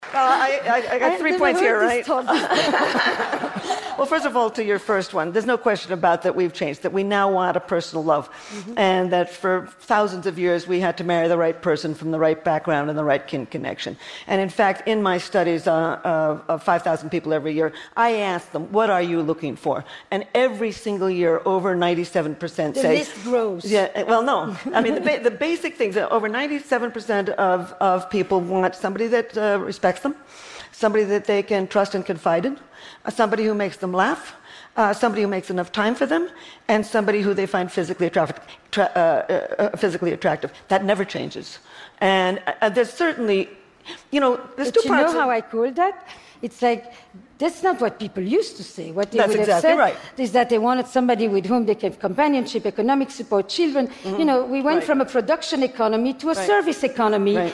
TED演讲:科技并没有改变爱 为什么?(11) 听力文件下载—在线英语听力室